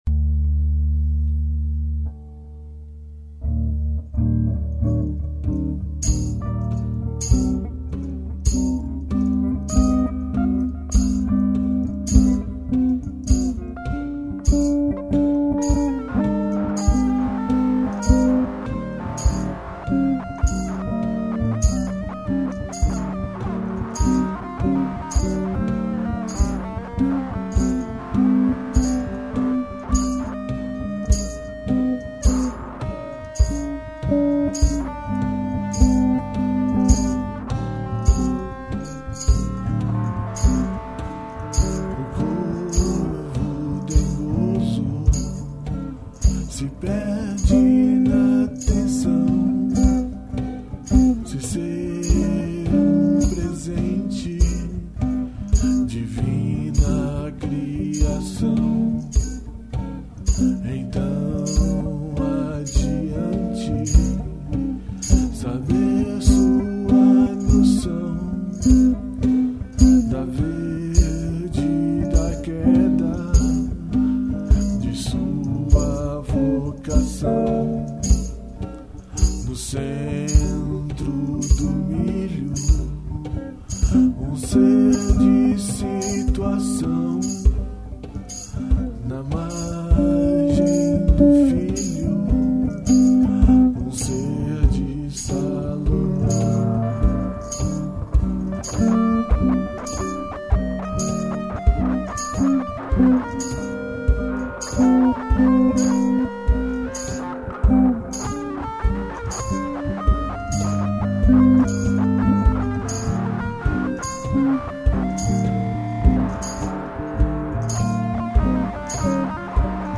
foram gravadas (as novas) no estúdio